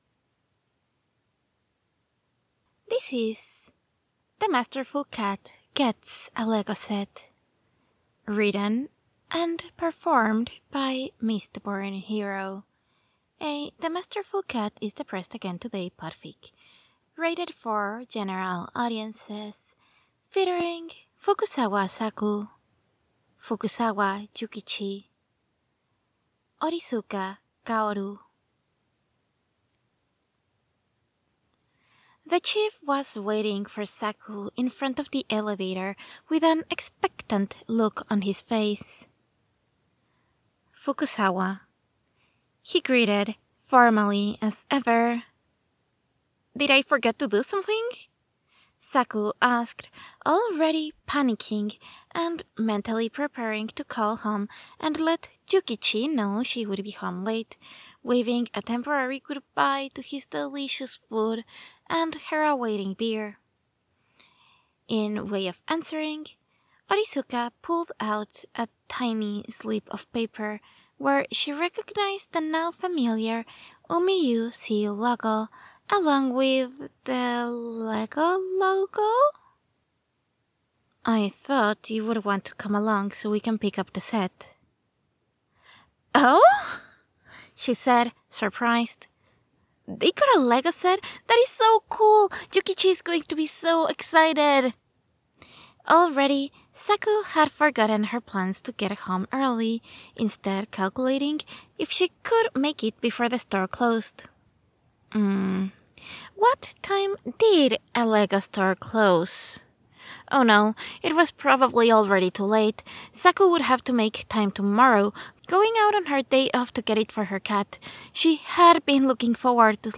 oral not!fic